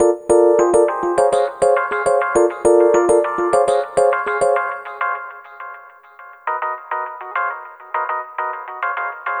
Ala Brzl 1 Piano-G.wav